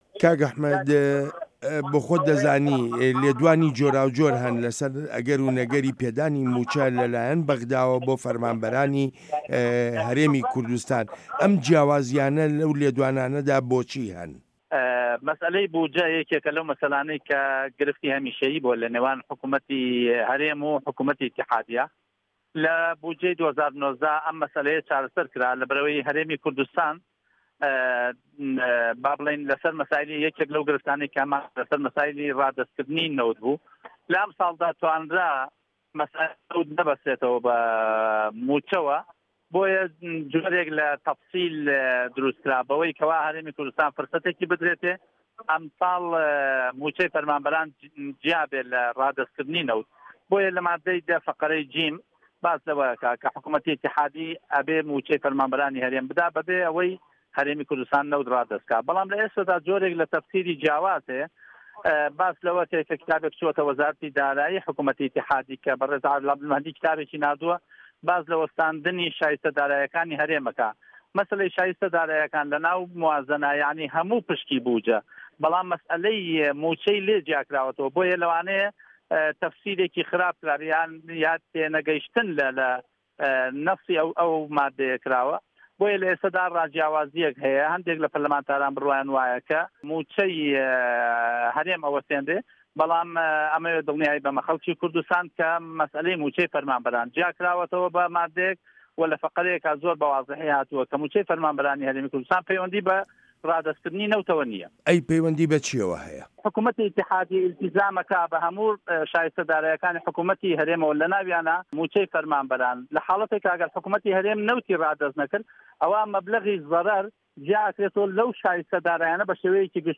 وتووێژ لەگەڵ ئەحمەد حاجی ڕەشید